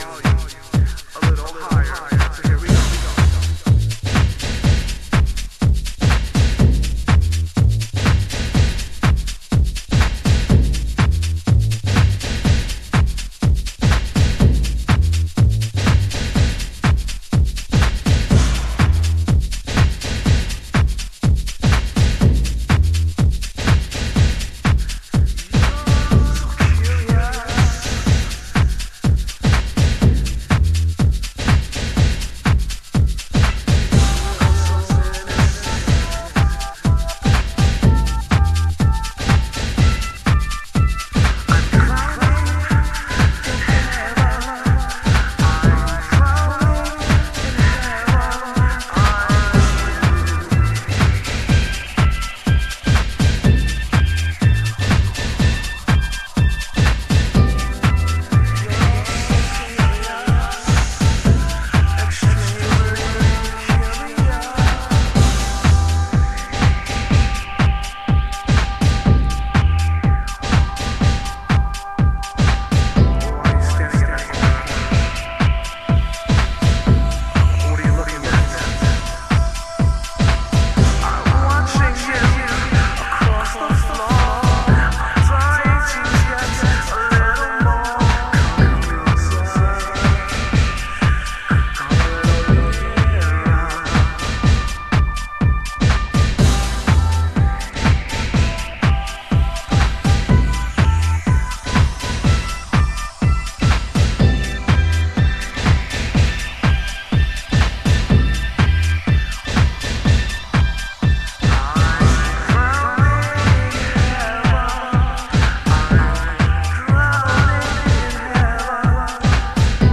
Early House / 90's Techno
勢いのあるユニット名、勢いのあるサウンド。